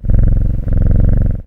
animalia_cat_purr.ogg